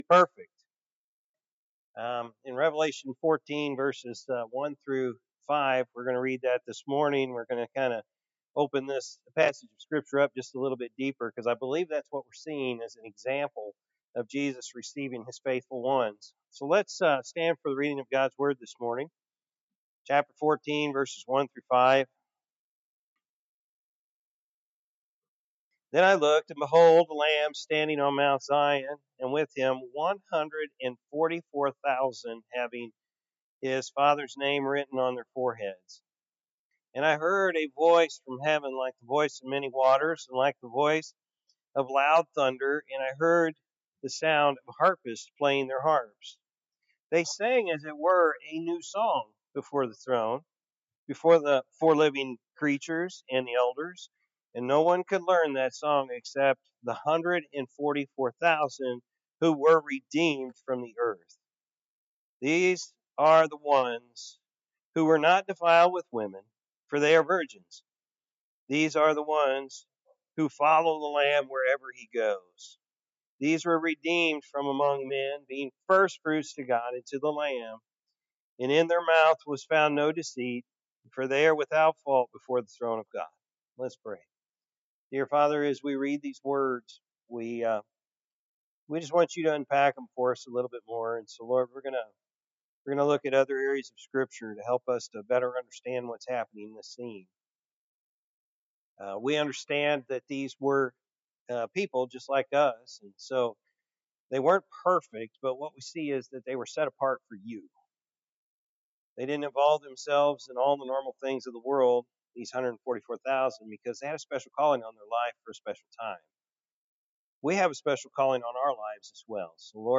February-9-2025-Morning-Service.mp3